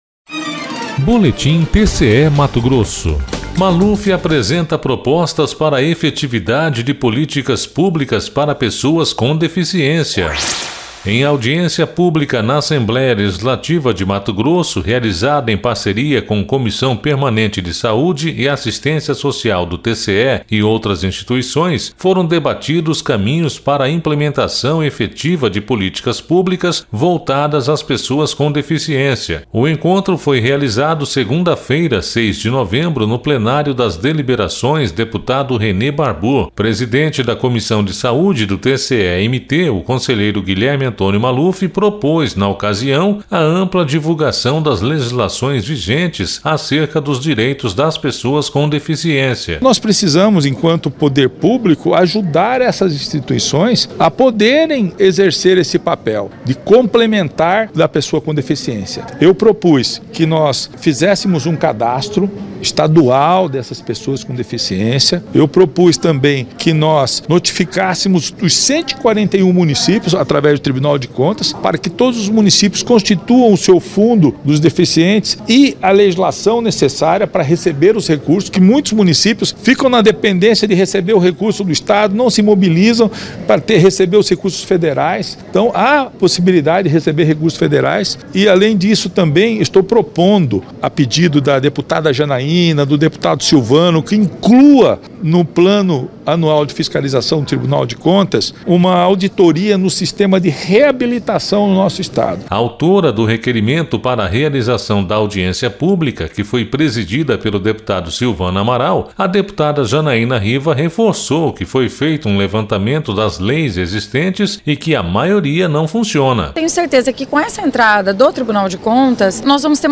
Em audiência pública na Assembleia Legislativa de Mato Grosso, realizada em parceria com Comissão Permanente de Saúde e Assistência Social do TCE e outras instituições, foram debatidos caminhos para a implementação efetiva de políticas públicas voltadas às pessoas com deficiência./ O encontro foi realizado segunda-feira, 06 de novembro no Plenário das Deliberações deputado Renê Barbour./ Presidente da Comissão de Saúde do TCE-MT, o conselheiro Guilherme Antonio Maluf propôs, na ocasião, a ampla divulgação das legislações vigentes acerca dos direitos das pessoas com deficiência.//
Sonora: Guilherme Antonio Maluf – conselheiro do TCE-MT
Sonora: Janaina Riva - deputada